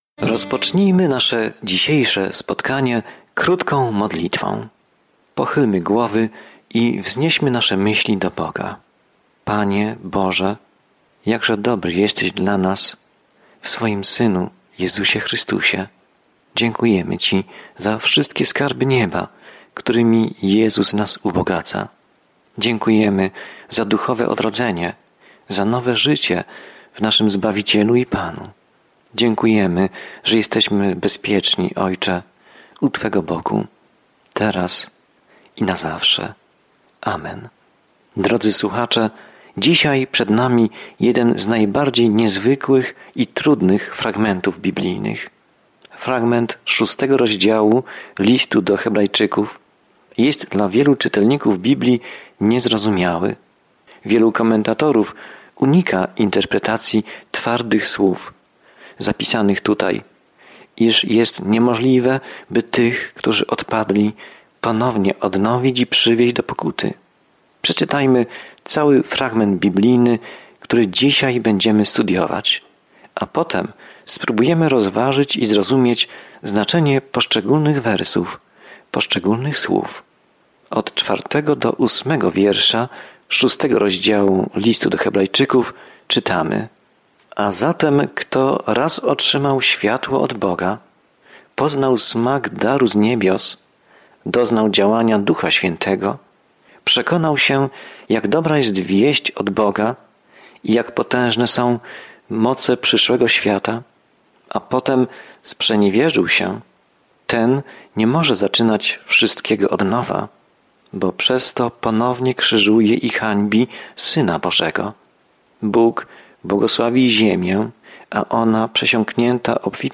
Codziennie podróżuj po Liście do Hebrajczyków, słuchając studium audio i czytając wybrane wersety słowa Bożego.